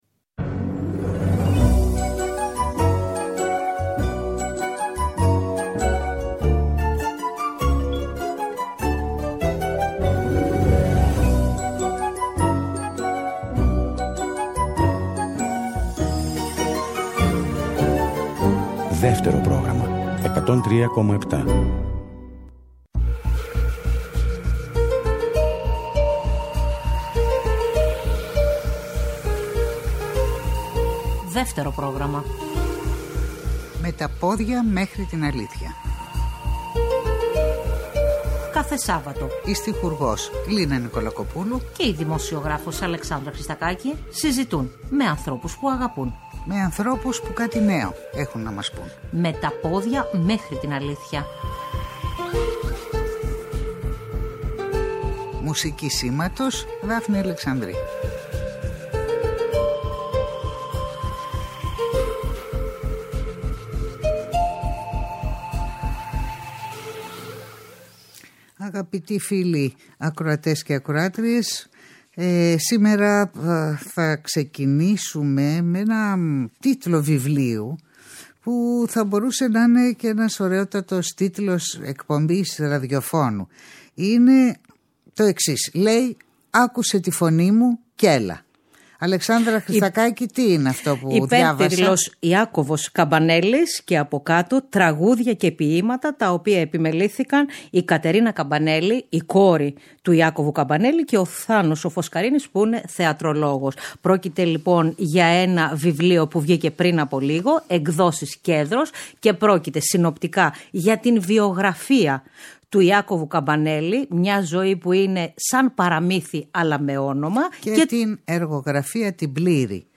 διαβάζουν εκτενή αποσπάσματα από το ένα και μοναδικό λογοτεχνικό του έργο “Μαουτχάουζεν”